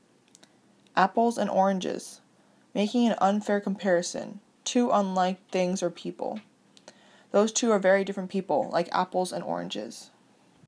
このように、本来、比較できない二人の人や二つの物を比較するという、誤った類推を行う場合に用いられます。 英語ネイティブによる発音は下記のリンクをクリックしてください。